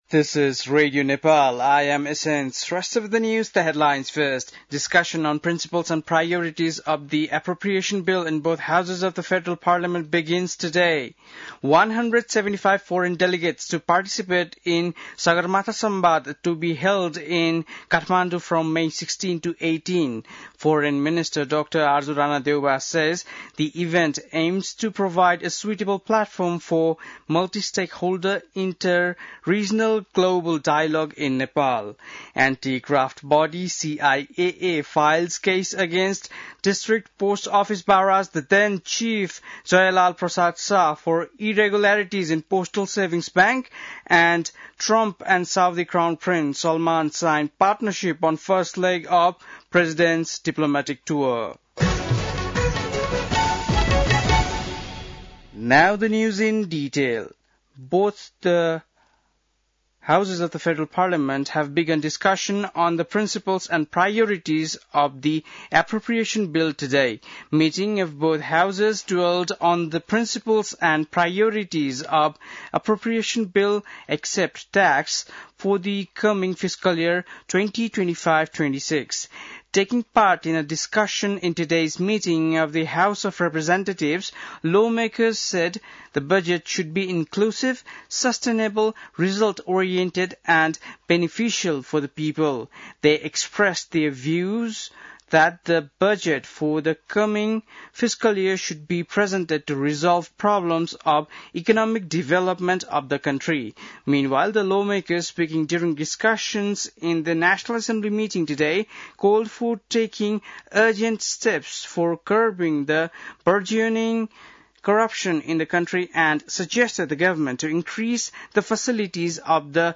बेलुकी ८ बजेको अङ्ग्रेजी समाचार : ३० वैशाख , २०८२